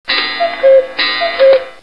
Cuckoo sound file attached